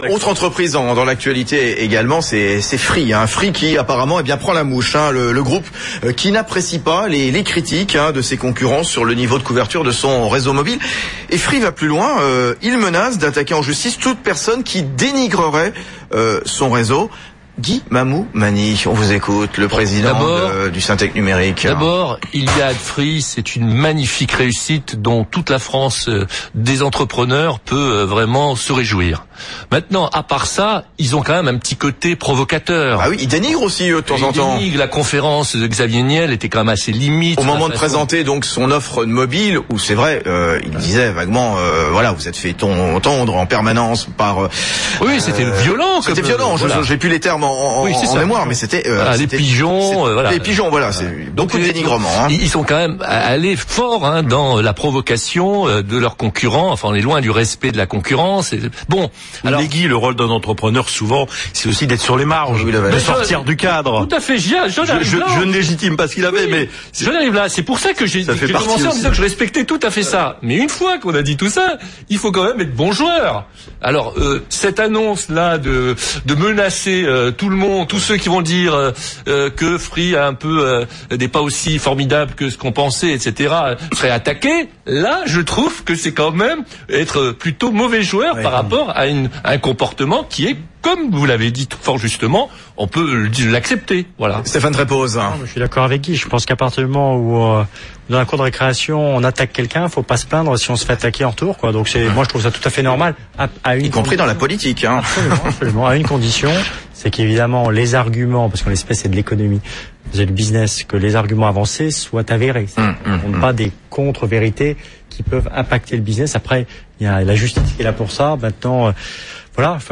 Le choix de ne pas révéler le nombre de clients abonnés à son offre, de la part de Free Mobile, fait beaucoup de déçus et de curieux. Jeudi soir, les invités de la radio éco BFM Business, analysaient cette décision dans Regards sur l’actu…